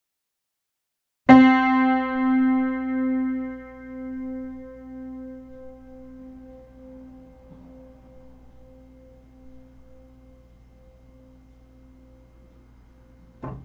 Middle C